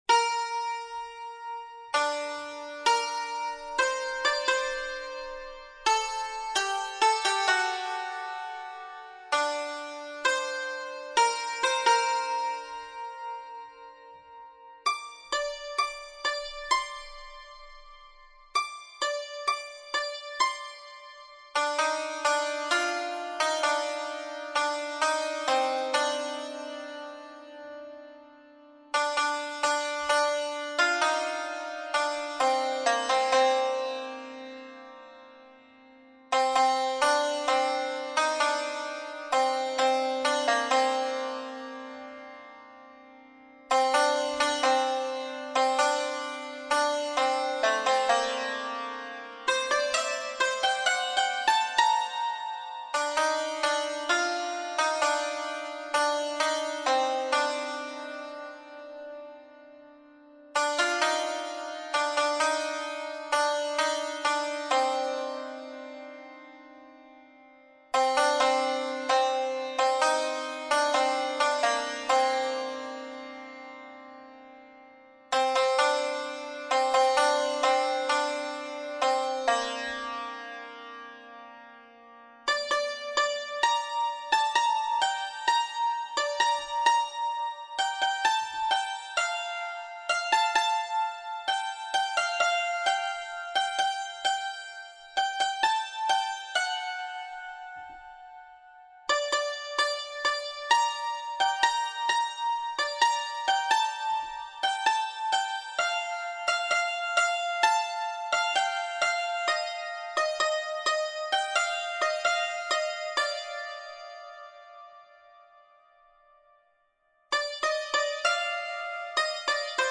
این نت جهت اجرا با سنتور نت نویسی شده است